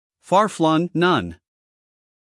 英音/ ˌfɑː ˈflʌŋ / 美音/ ˌfɑːr ˈflʌŋ /